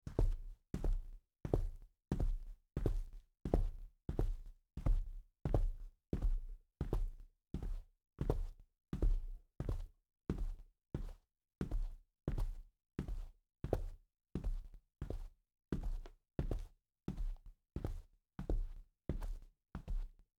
Звуки ковра
Шаги по ковру